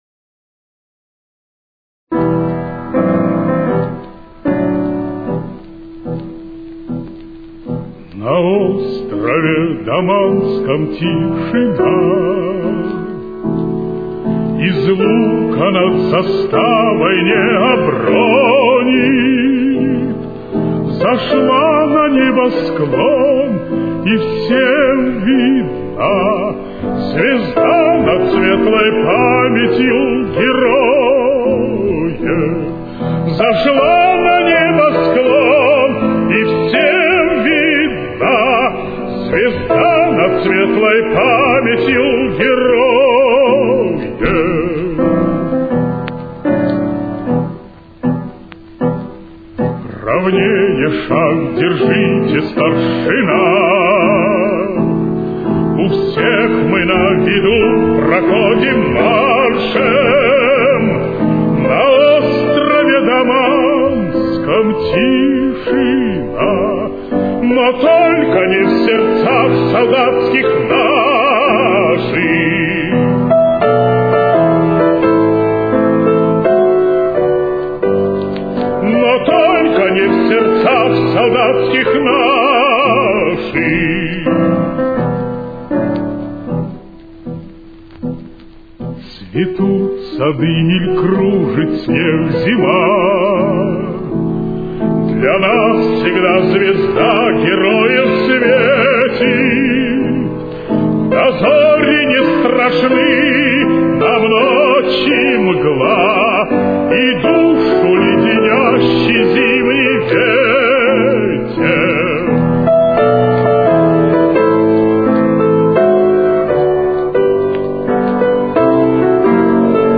с очень низким качеством (16 – 32 кБит/с)
Темп: 80.